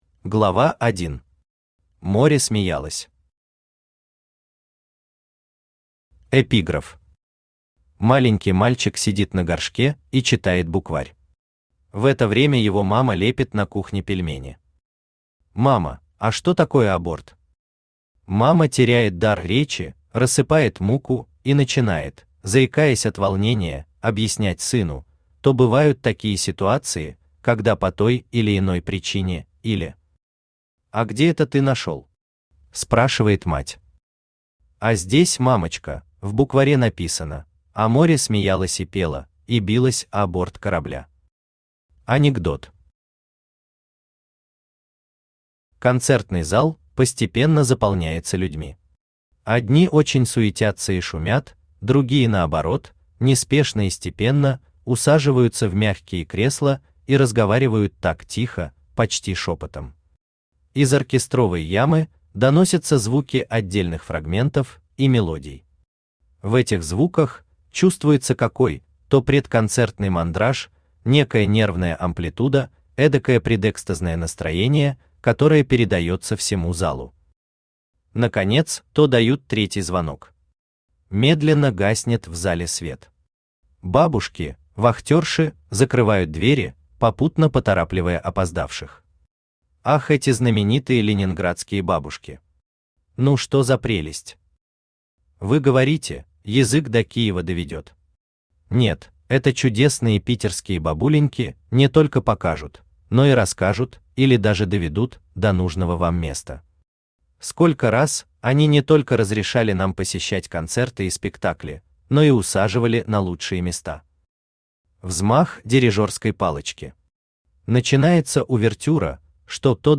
Аудиокнига Море смеялось | Библиотека аудиокниг
Aудиокнига Море смеялось Автор Константин Николаевич Кудрев Читает аудиокнигу Авточтец ЛитРес.